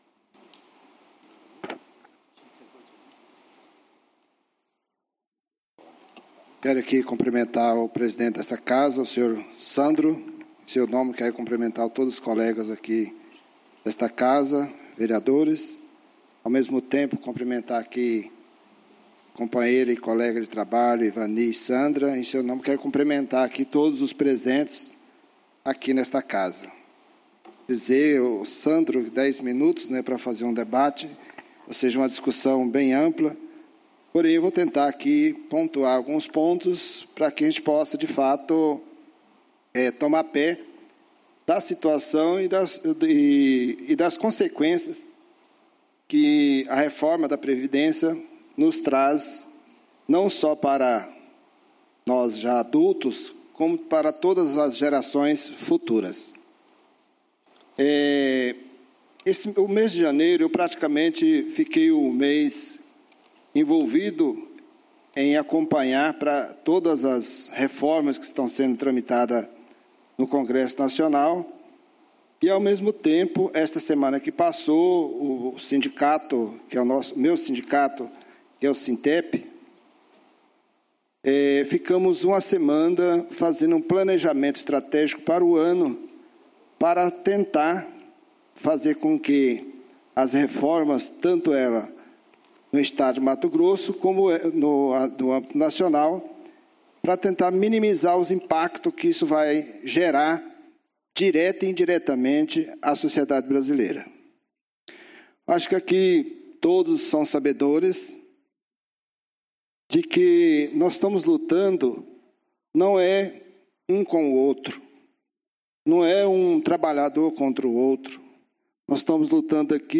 Áudio do Grande Expediente da Sessão Ordinária do dia 13/02/2017 utilizado pelo vereador Carlito da Rocha Pereira